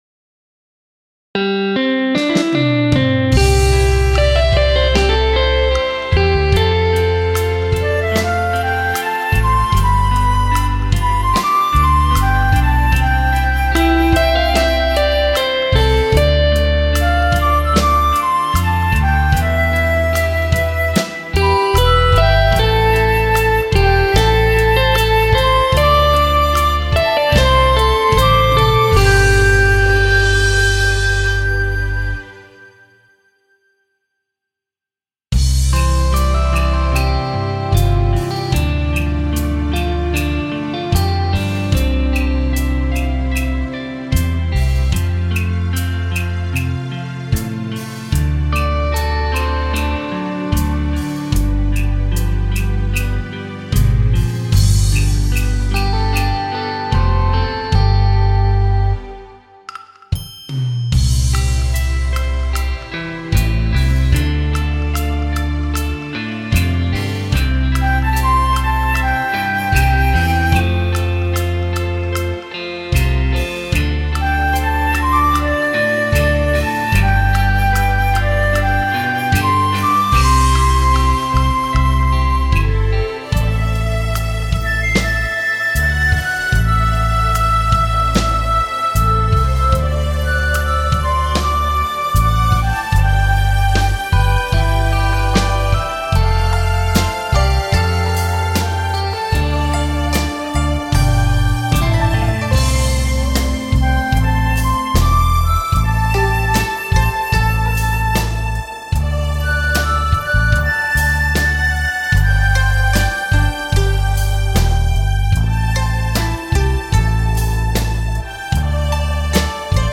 Nghe nhạc hòa tấu karaoke: